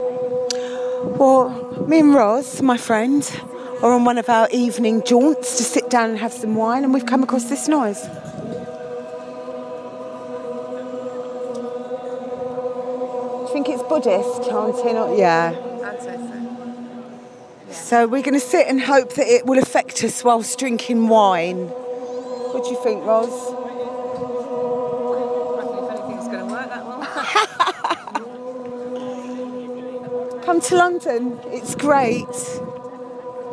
Buddhist Chanting on the river